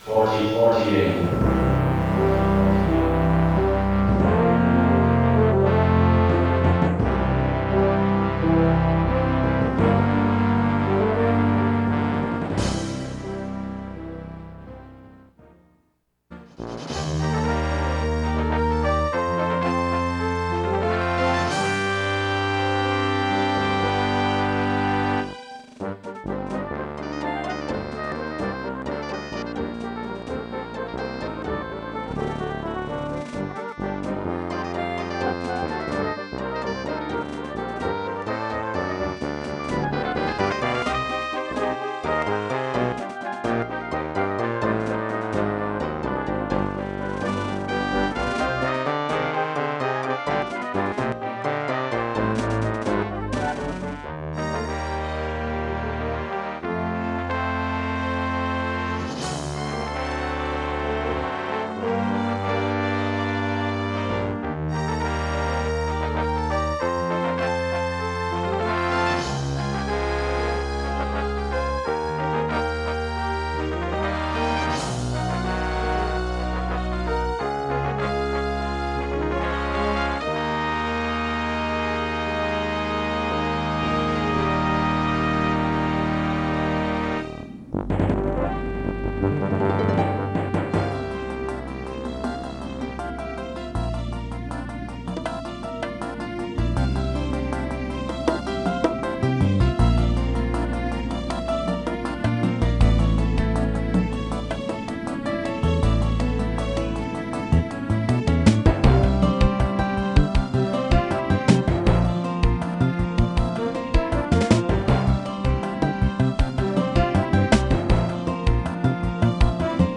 old school game and demo music http